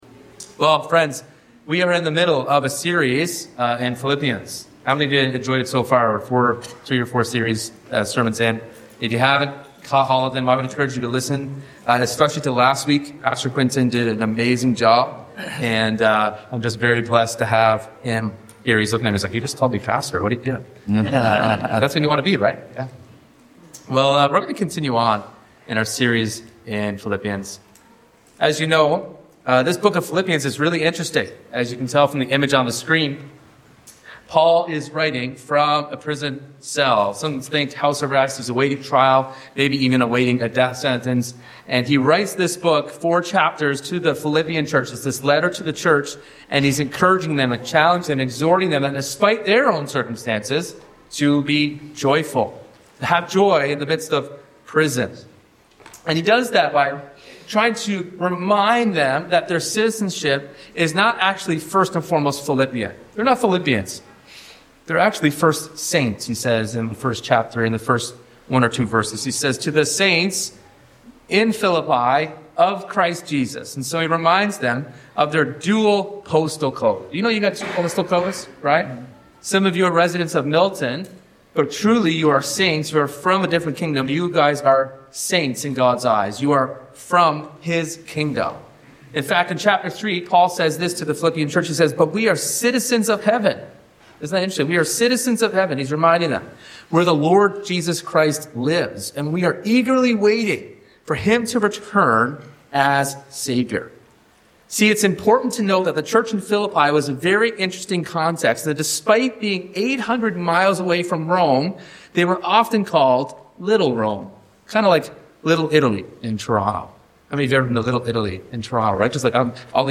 This sermon unpacks what it means to stand firm with courage, consistency, and unity in a culture that may not share our convictions.